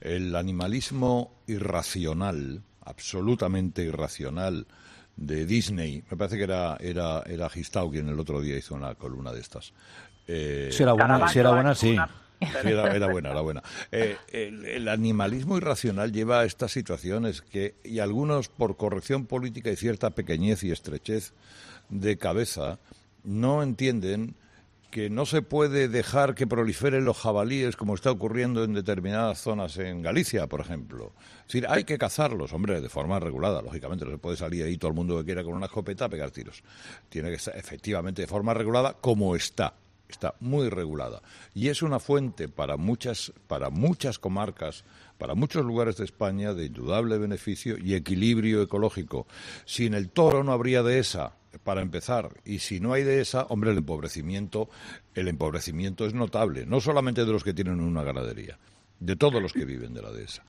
Escucha el rotundo comentario de Herrera sobre los ataques al mundo rural